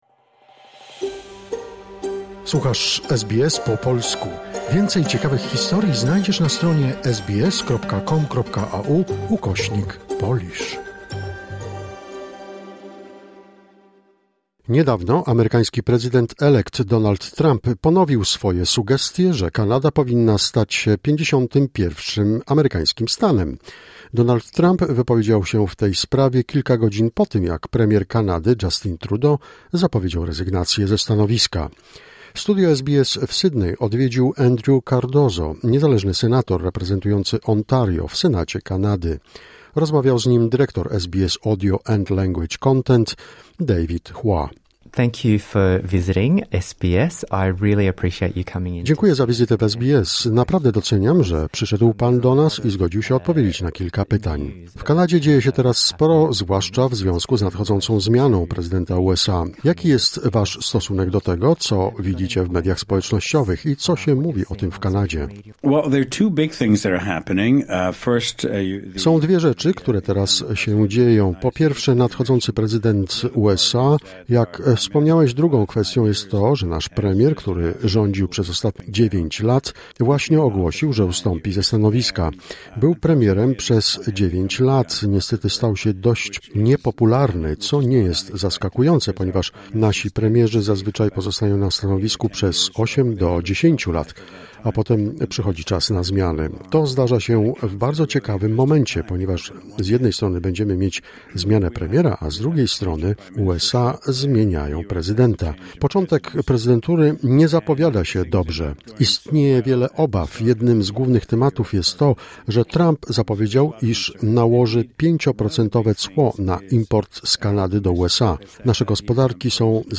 Studio SBS w Sydney odwiedził Andrew Cardozo, niezależny senator reprezentujący Ontario w Senacie Kanady.